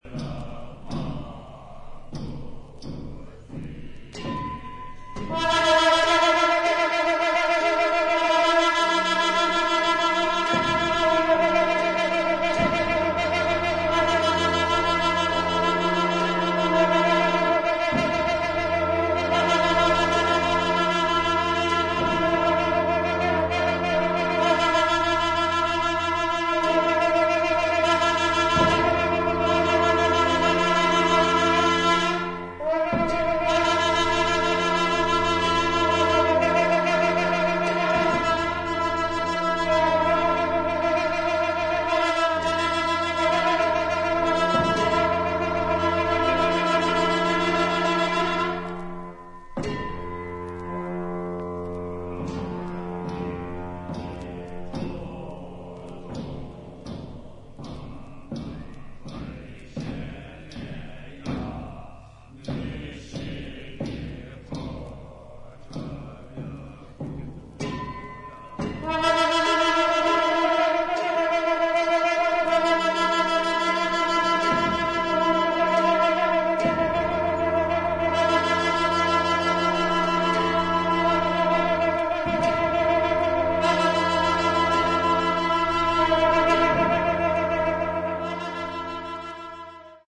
本作は、ブータンの伝統的な宗派「ドゥプカ派」の儀式に焦点を当て、総勢76人のラマ僧と僧侶が神聖な寺院で長いトランペット、ショーム、シンバル、太鼓などのチベット楽器を用いて詠唱し、演奏。臨場感あふれる声明や打楽器の演奏、メディテーティブな僧院音楽が生々しく記録された大変貴重な音源です。